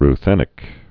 (r-thĕnĭk, -thēnĭk)